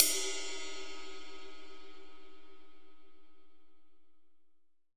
Index of /90_sSampleCDs/AKAI S6000 CD-ROM - Volume 3/Ride_Cymbal2/JAZZ_RIDE_CYMBAL